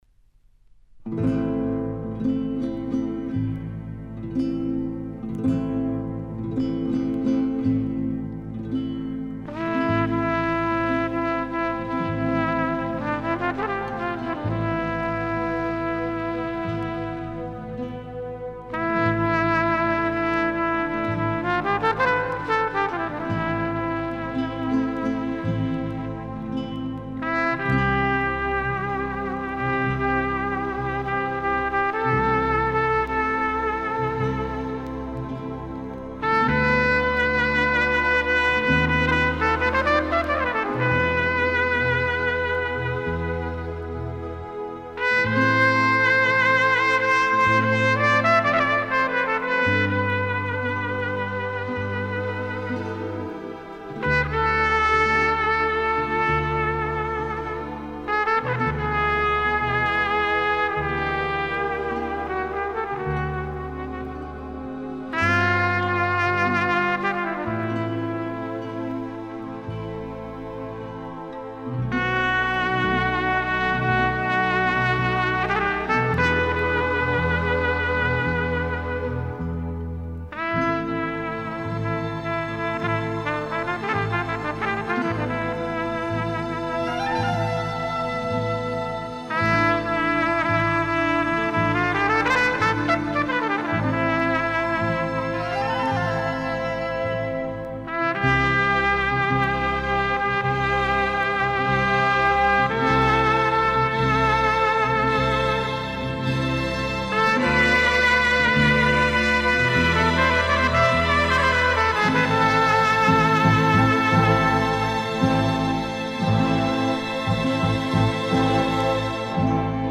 играет с "переливами".
Отсутствуют ударные инструменты.
Очень богатая аранжировка струнных смычковых.
С 1:25 - диалог трубы и флейты (либо кларнета).